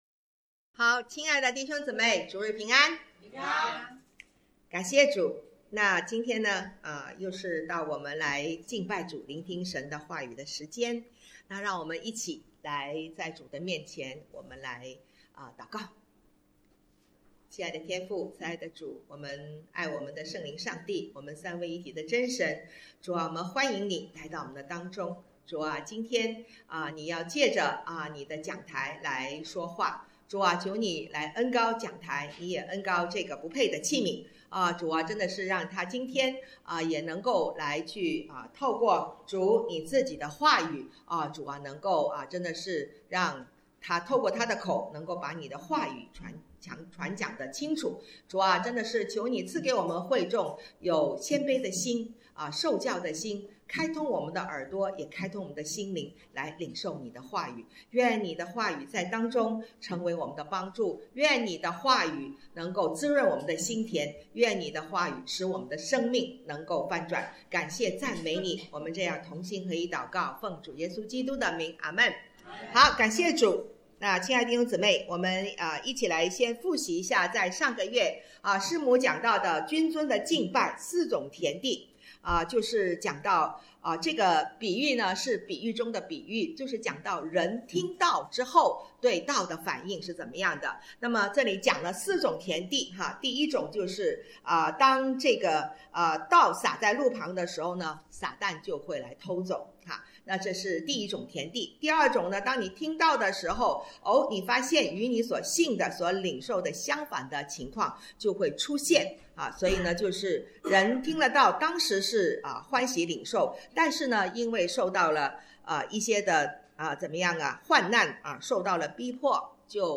讲道录音